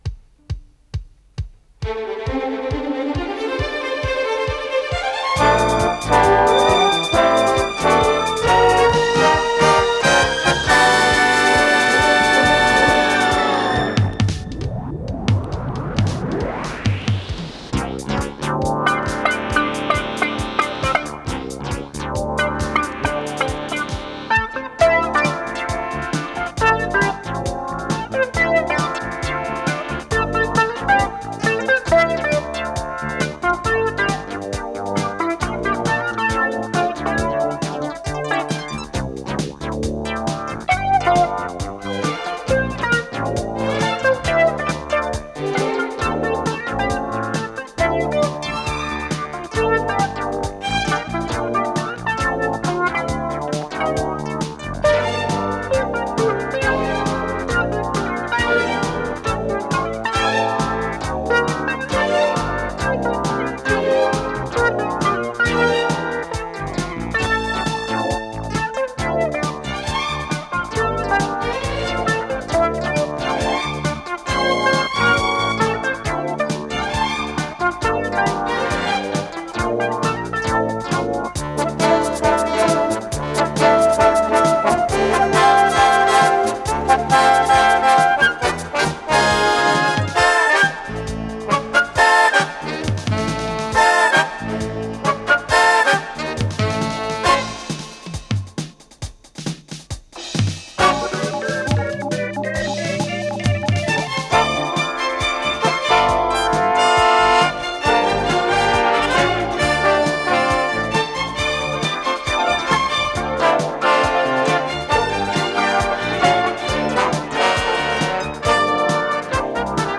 с профессиональной магнитной ленты
ПодзаголовокРе минор
ВариантДубль моно